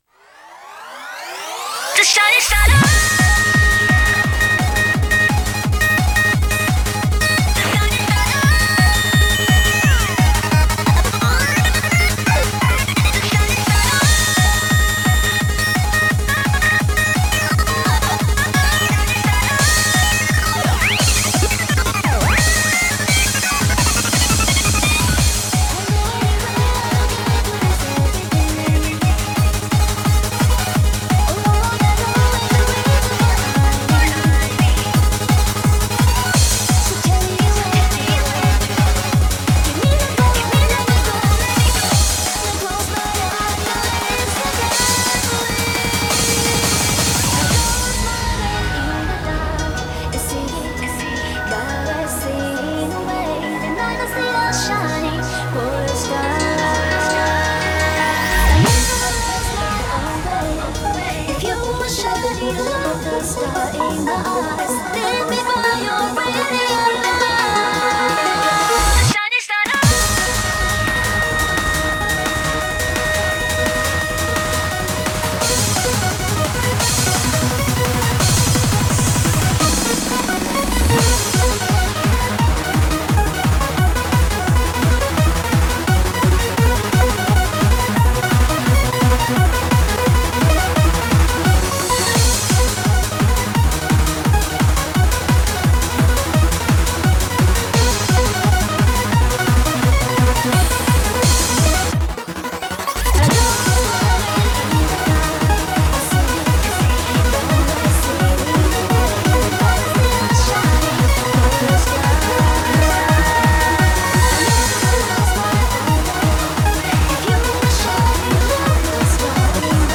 BPM172